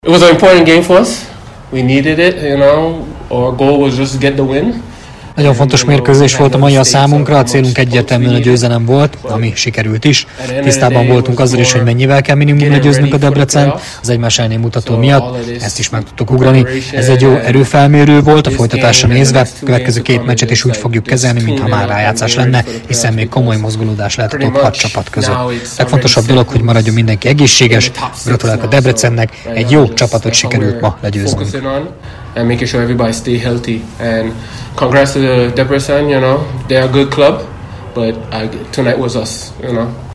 a mérkőzés utáni sajtótájékoztatón